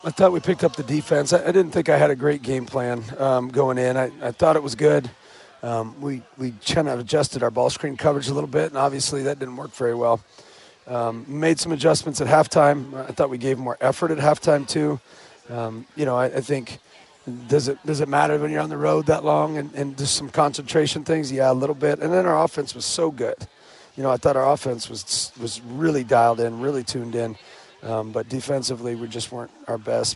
That's Iowa coach Ben McCollum who says the Hawkeyes picked it up defensively in the second half.